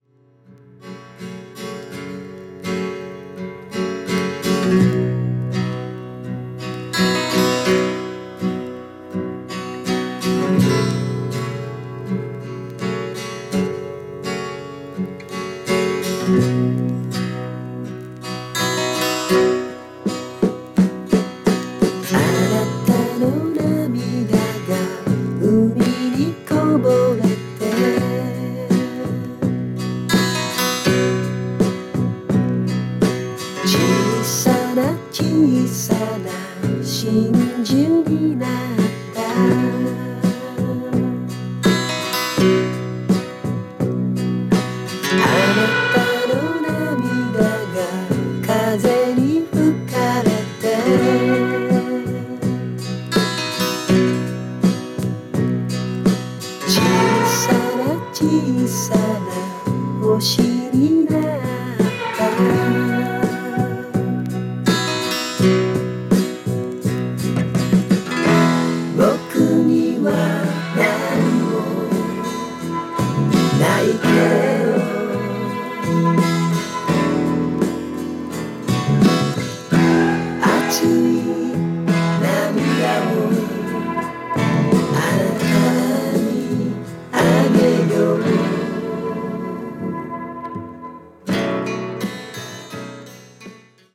Japanese Acid Folk！！！